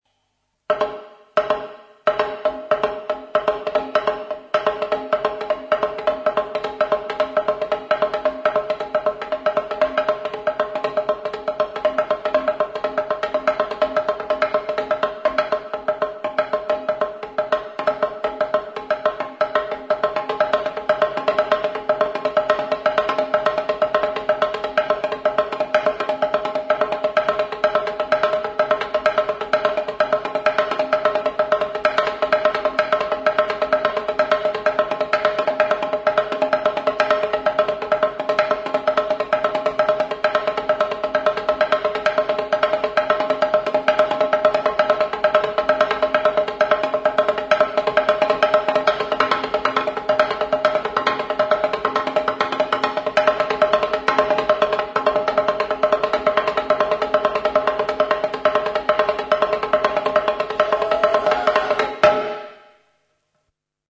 txalapartaThe txalaparta is played by two musicians.
This goes on back and forth, setting and breaking the rhythm throughout the session; the pace gradually quickens until an unbreakable order and balance is attained.
One of the musicians plays the part known as ttakun or tukutun, which consists of two strokes repeated over and over again.
Txalaparta-1min.mp3